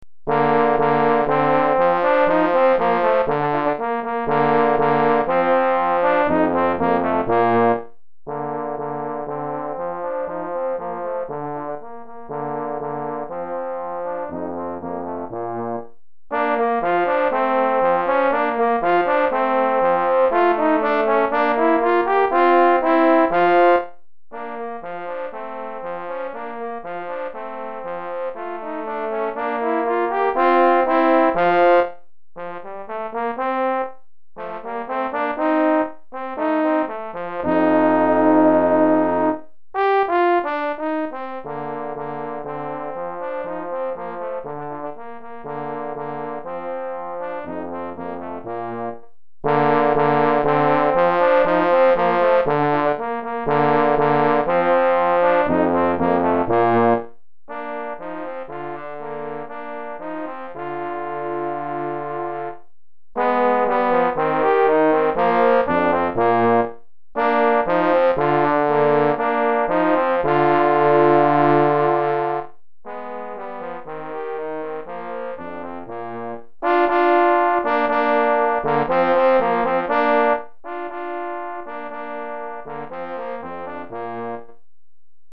2 Trombones